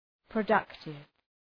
Προφορά
{prə’dʌktıv}